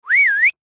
whistle.mp3